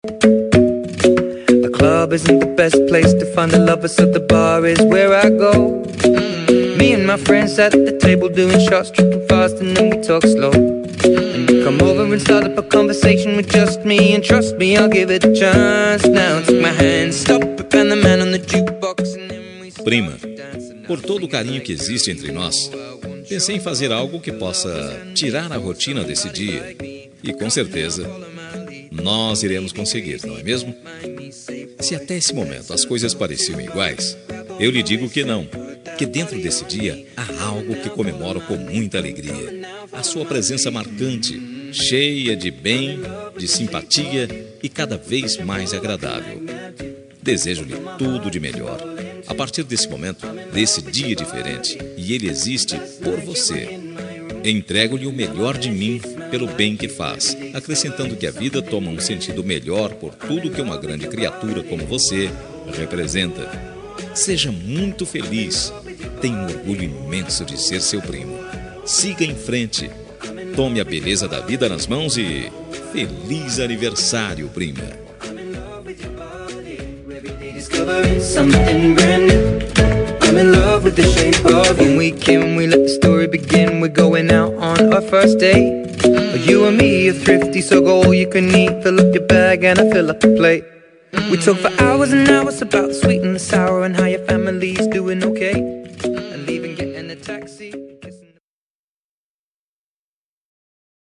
Aniversário de Prima – Voz Masculina – Cód: 042812